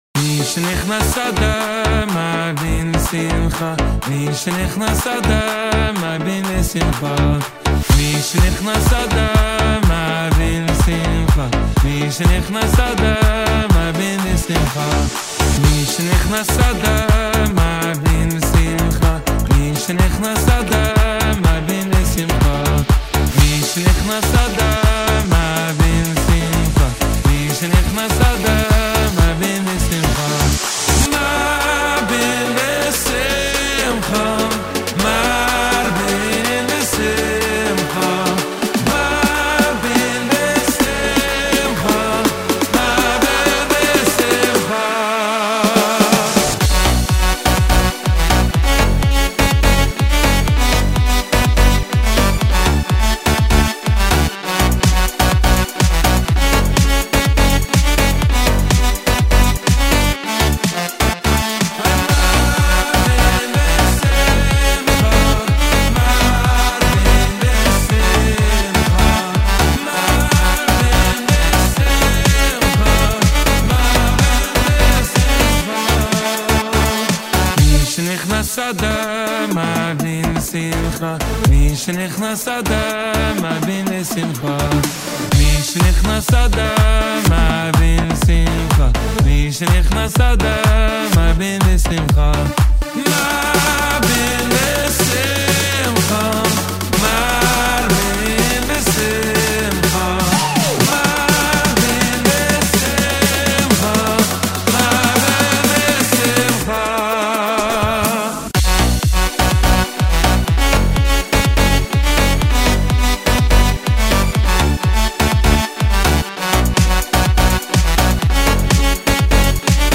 יש קצת בעיות קצב באתחלה